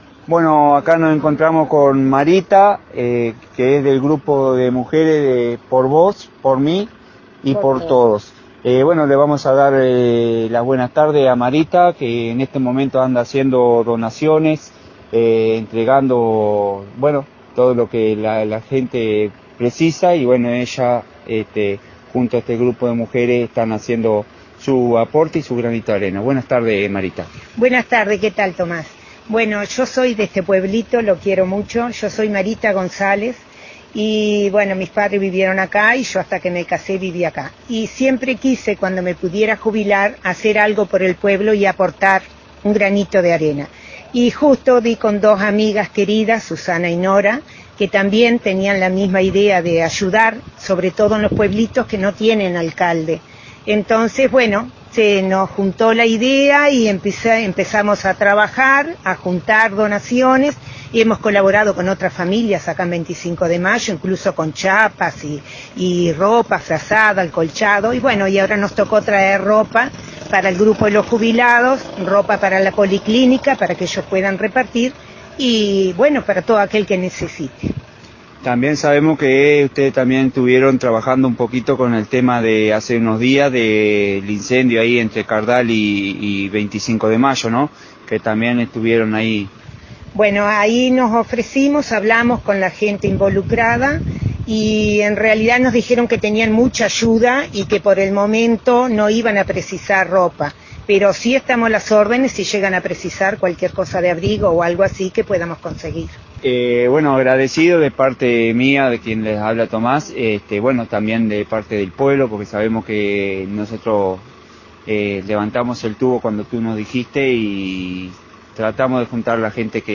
Entrevista-Por-vos-por-mi-por-todos.mp3